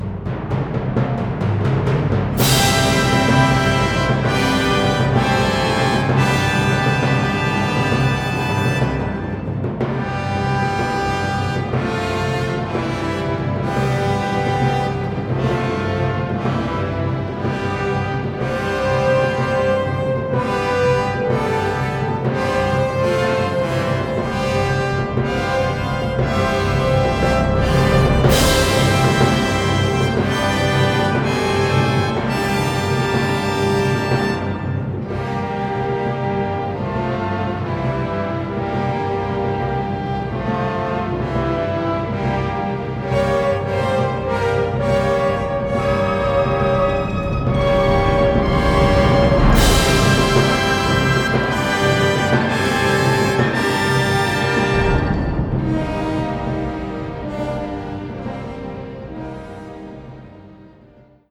but rather relies on a large orchestra.